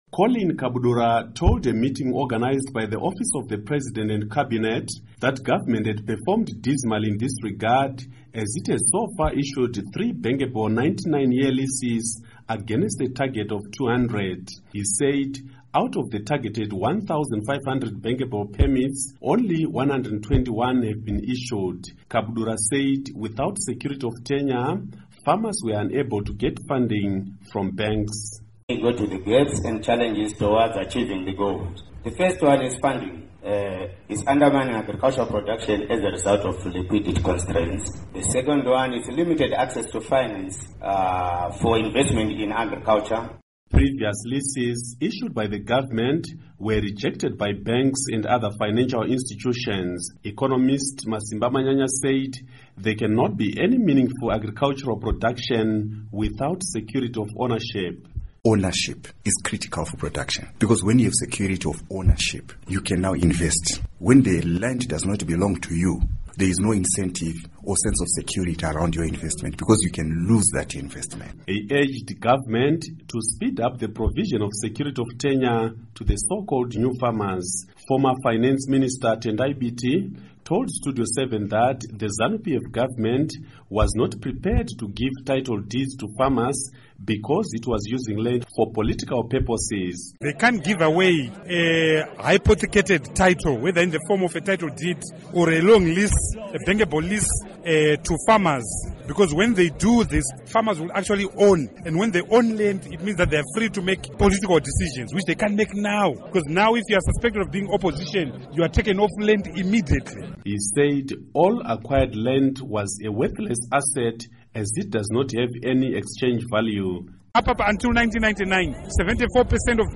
Report on Land Security